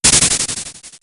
BW_flee.wav